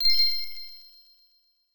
Coins (4).wav